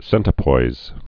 (sĕntə-poiz)